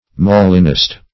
Search Result for " molinist" : The Collaborative International Dictionary of English v.0.48: Molinist \Mo"lin*ist\, n. (Eccl.